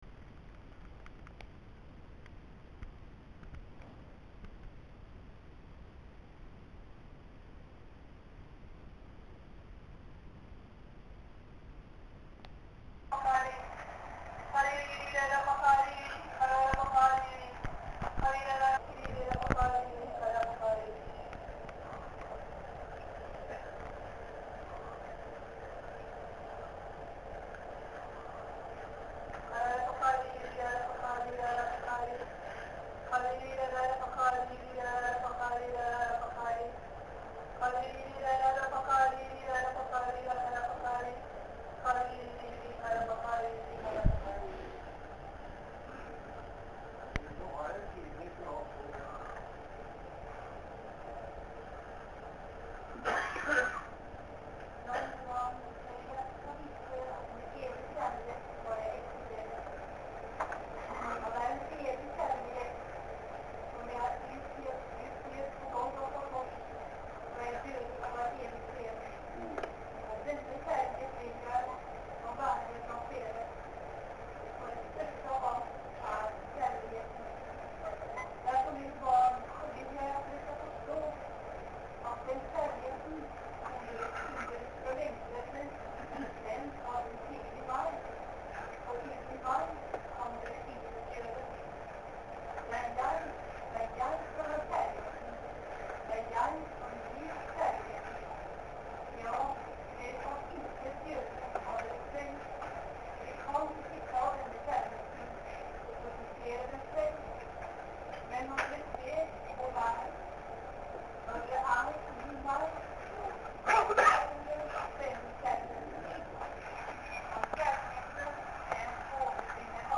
Møte i den Frie Evangeliske Forsamling i Møllergata 40 i Oslo, laurdag 12.2.2011.